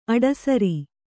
♪ aḍasari